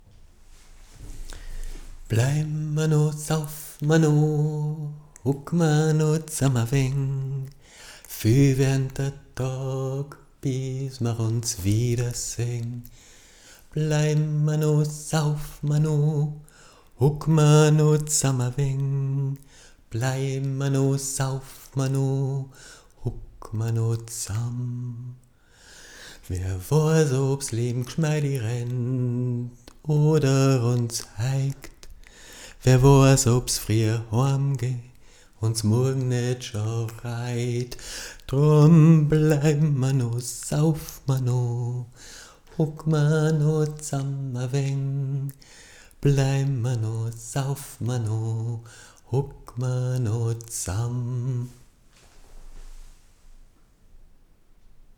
- ♫ Erste Audio-Skizze ♫